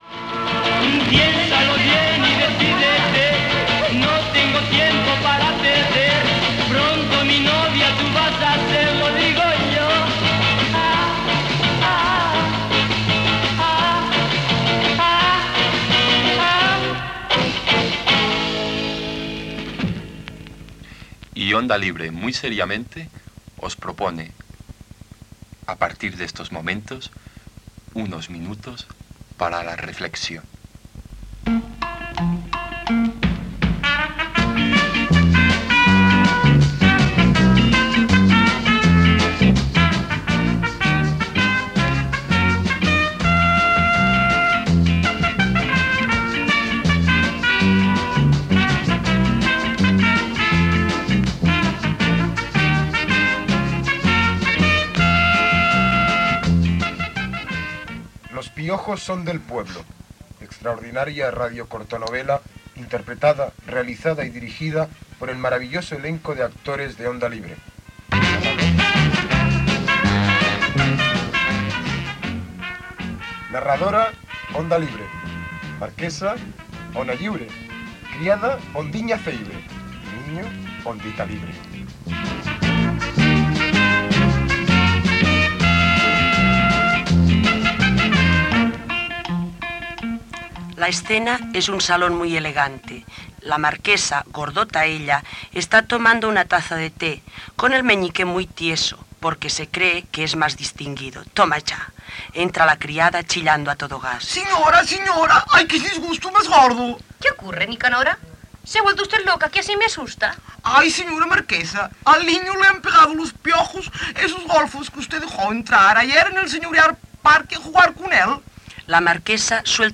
Gènere radiofònic Ficció